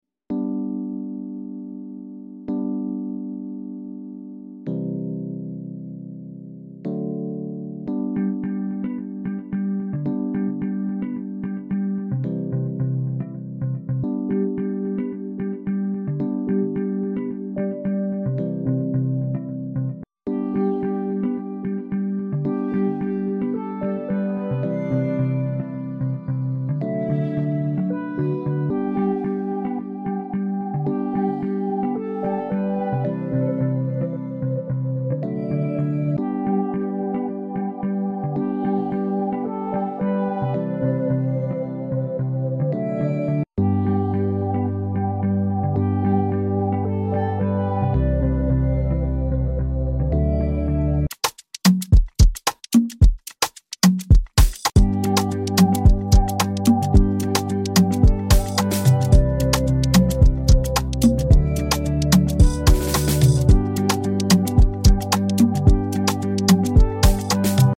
Afrobeat Fl Studio tutorial sound effects free download